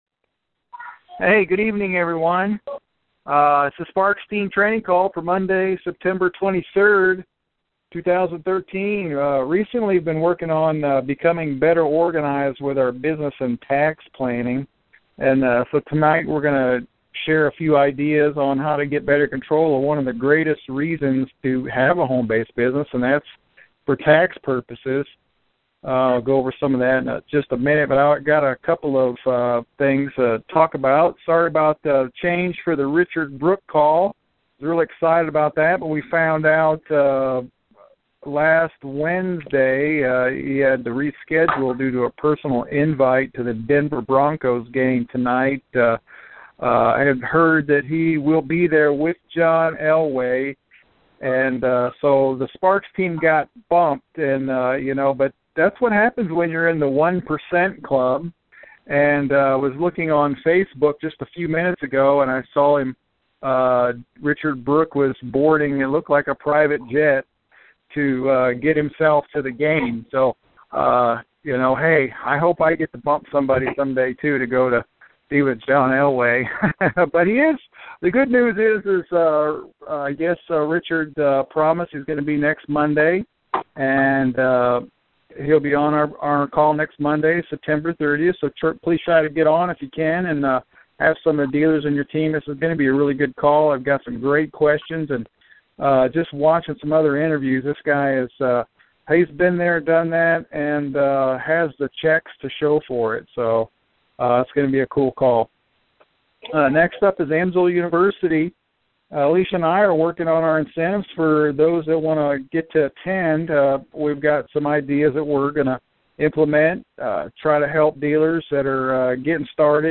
Team Training Call